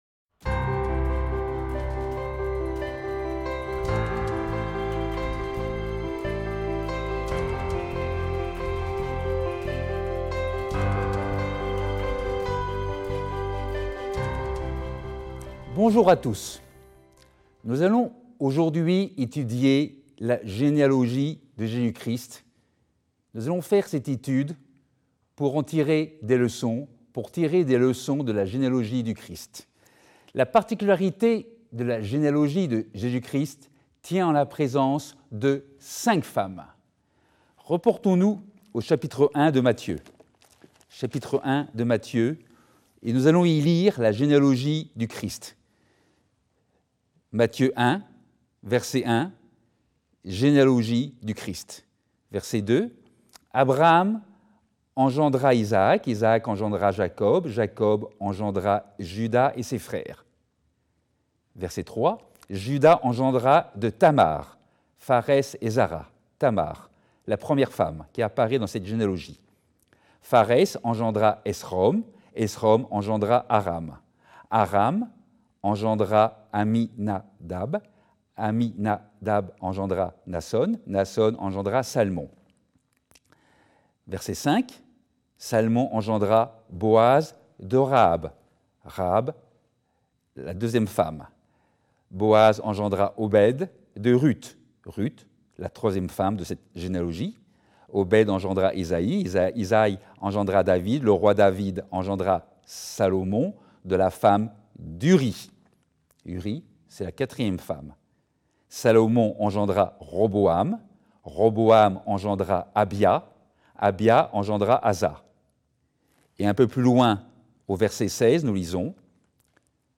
Sermons
Given in Bordeaux